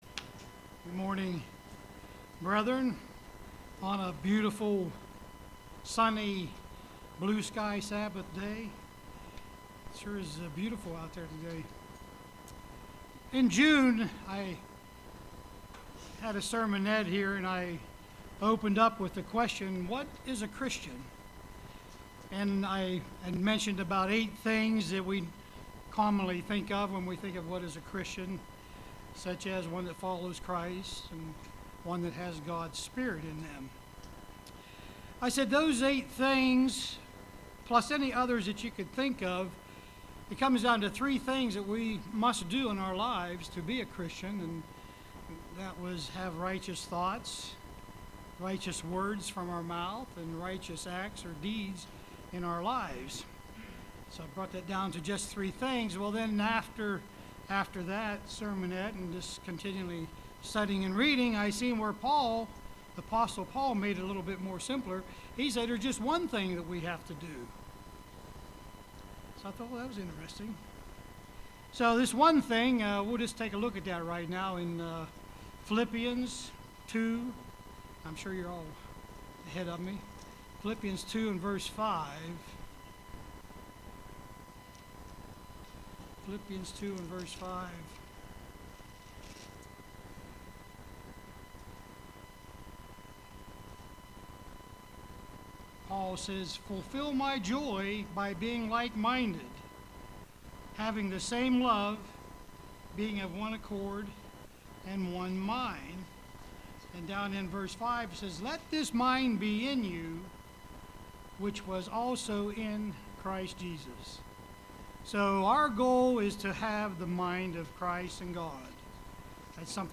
Print Love What God Loves UCG Sermon